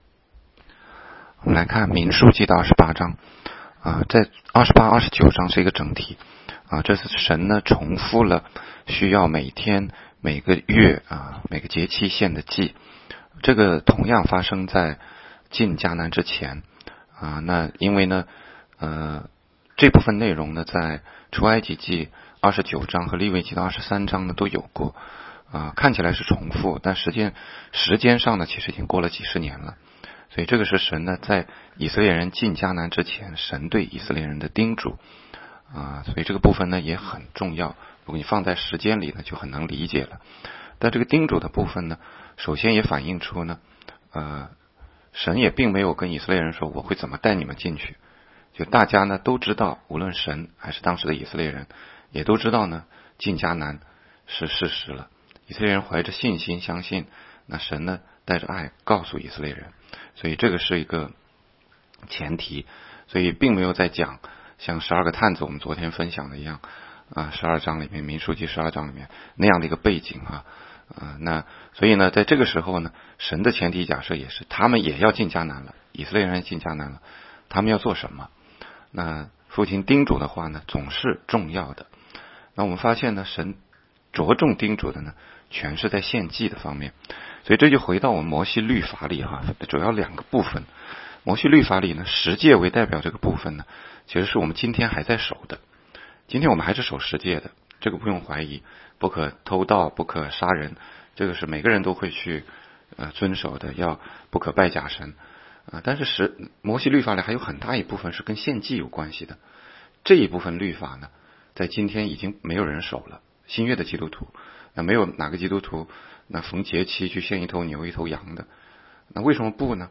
16街讲道录音 - 每日读经
民数记第28章逐节注解、祷读